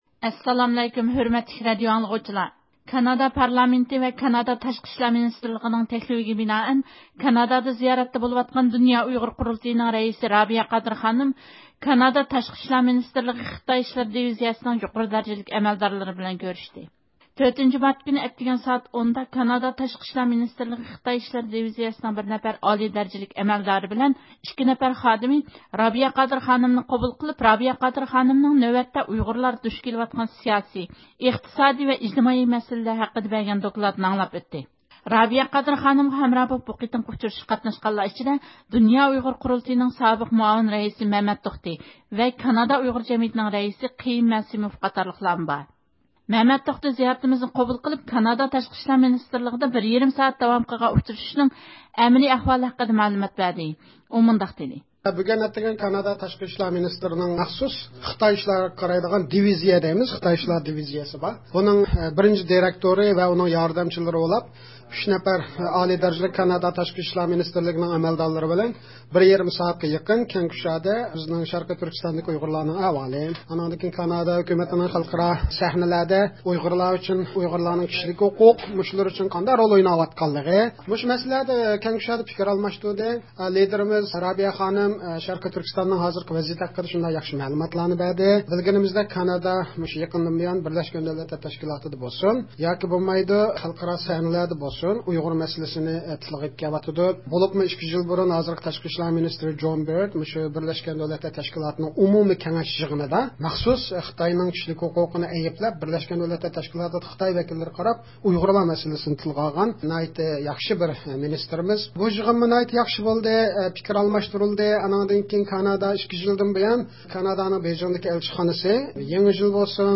زىيارىتىمىزنى قوبۇل قىلىپ، كانادا تاشقى ئىشلار مىنىستىرلىقىدا داۋام قىلغان ئۇچرىشىشلارنىڭ ئەمەلىي ئەھۋالى ھەققىدە مەلۇمات بەردى.